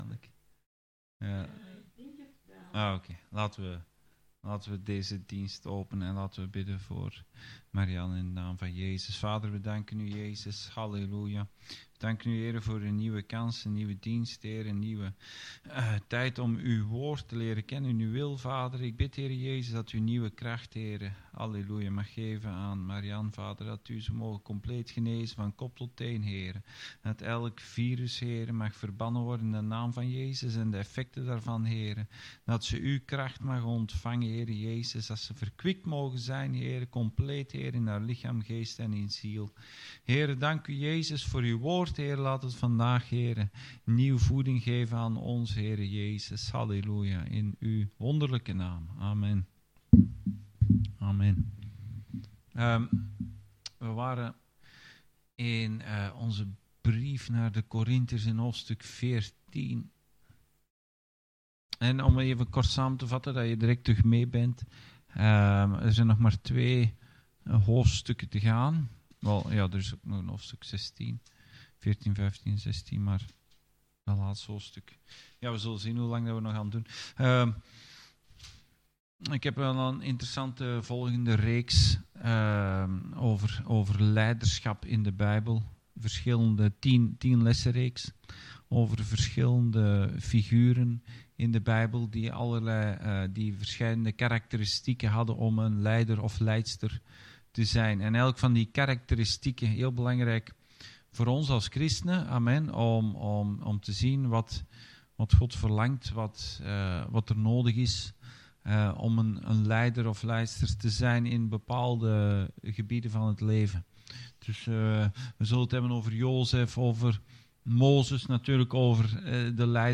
Bijbelstudie: 1 Korintiërs 14